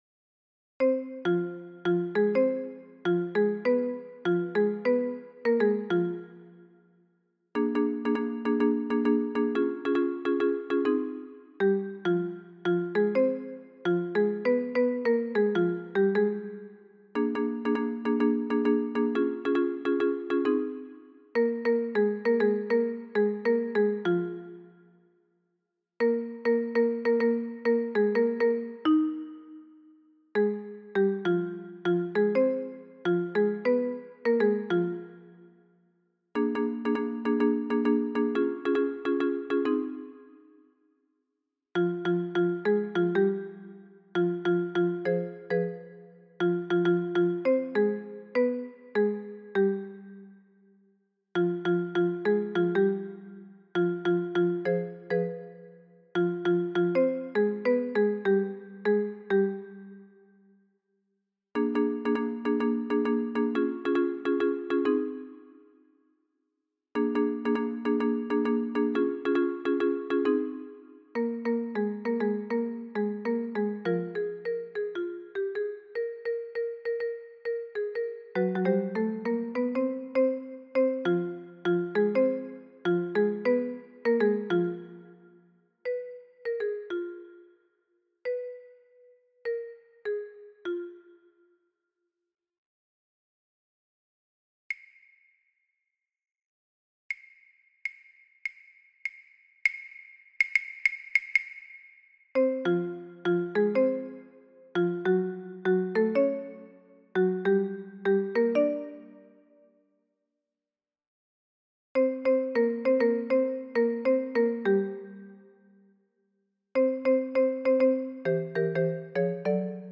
Mp3 version instrumentale
Tutti